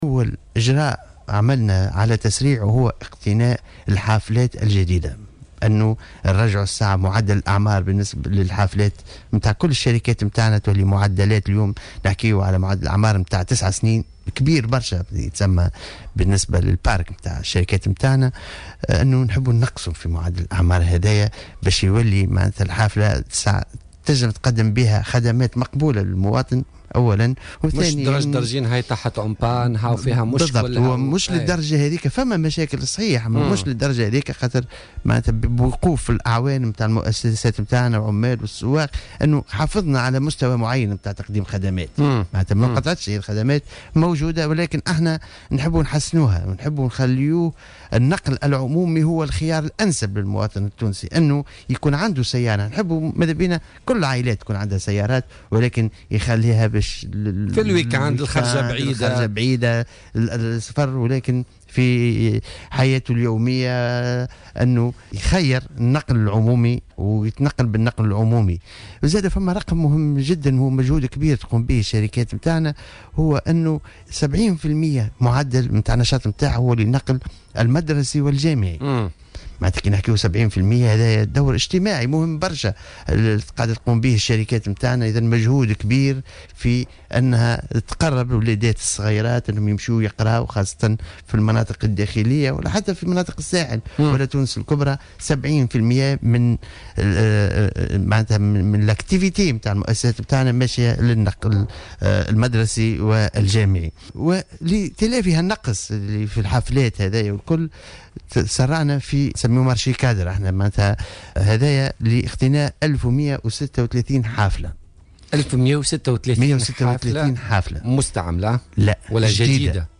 أكد وزير النقل أنيس غديرة في تصريح للجوهرة أف أم في برنامج بوليتكا لليوم الاثنين 23 ماي 2016 أن شركة النقل ستعزز أسطولها بـ1136 حافلة جديدة لتلافي النقص المسجل في عدة جهات.